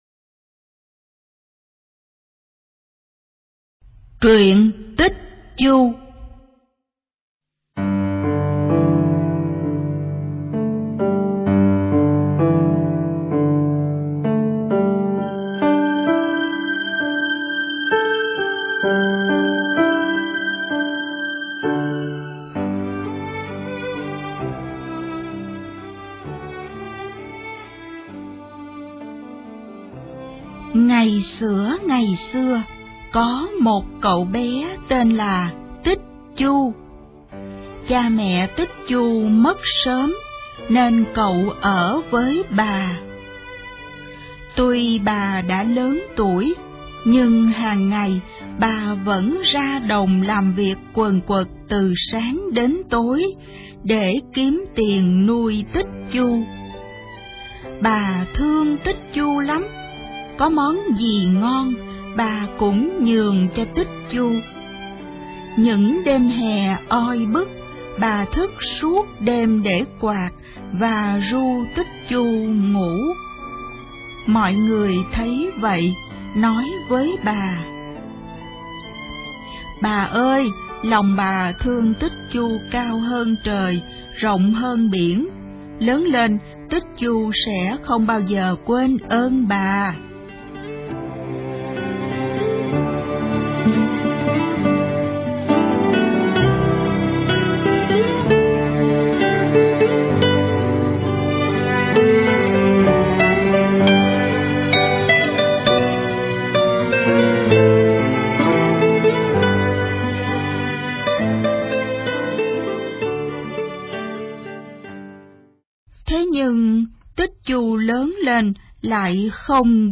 Sách nói | Truyện Cổ Tích Việt Nam P13